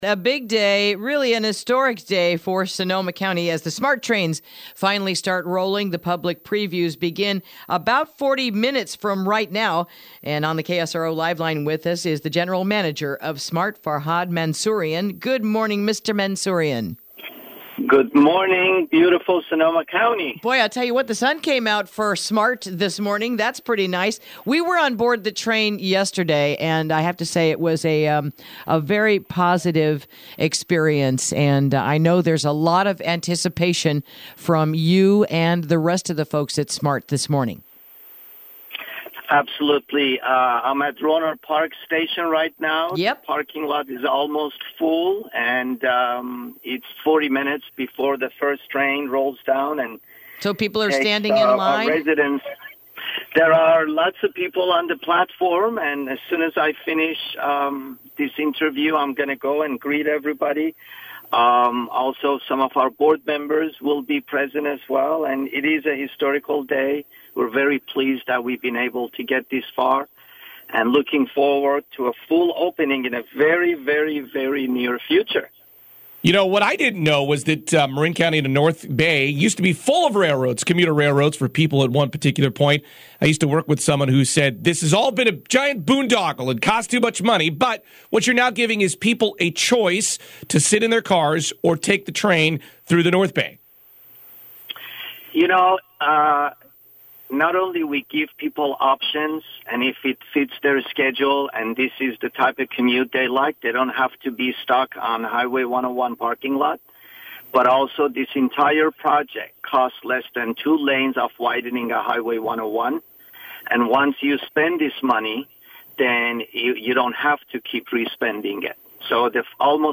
Interview: SMART Preview Starts Today